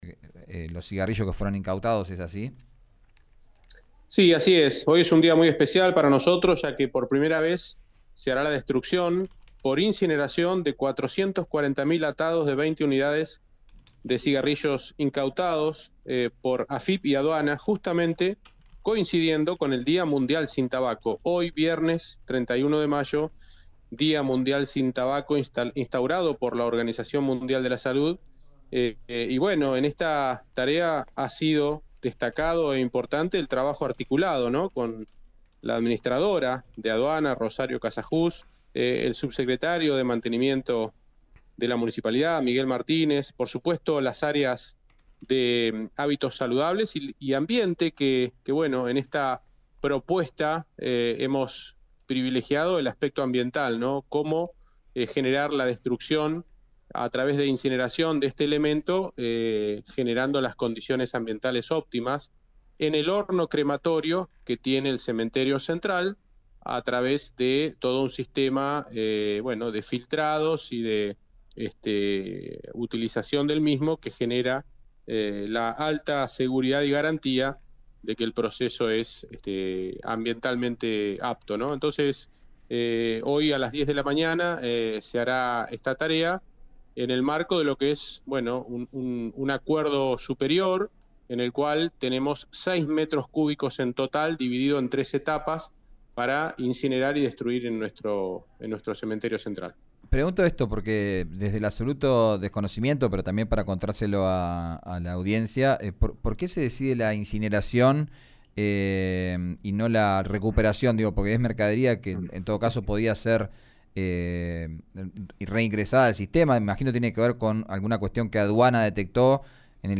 El subsecretario de Medio Ambiente y Protección Ciudadana Francisco Baggio, habló sobre la actividad en RÍO NEGRO RADIO.
Escuchá a Francisco Baggio, subsecretario de Medio Ambiente y Protección Ciudadana de Neuquén, en RÍO NEGRO RADIO: